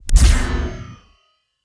SCIEnrg_Shield Activate_01_SFRMS_SCIWPNS.wav